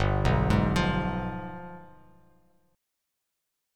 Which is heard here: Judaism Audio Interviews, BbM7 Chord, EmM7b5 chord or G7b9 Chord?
G7b9 Chord